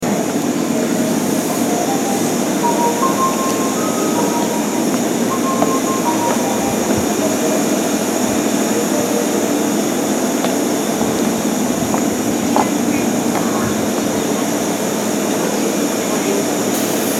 谷保駅　Yaho Station ◆スピーカー：Roland
その上、ホームが狭いため録りづらいです。
1番線発車メロディー